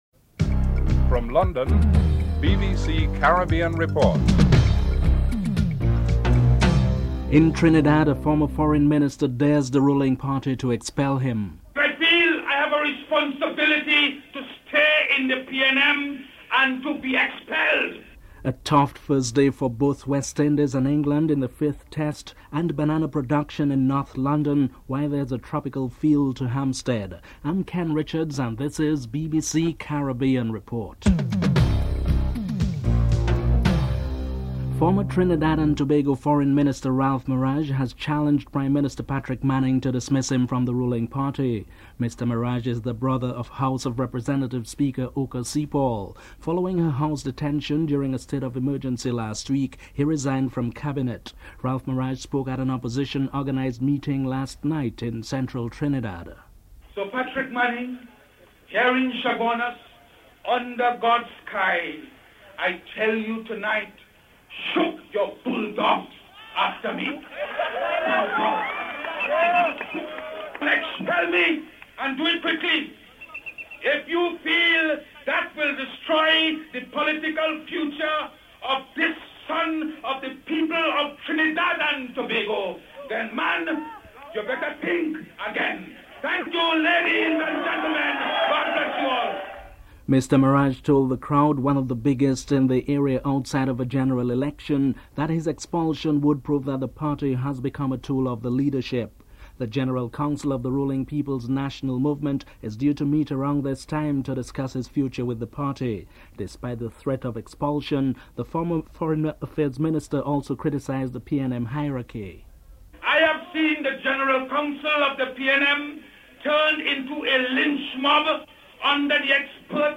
dc.description.tableofcontents5. Montserrat's Chief Minister comments about the continuing volcanic threat to the island (06:04-07:12)en_US
dc.typeRecording, oralen_US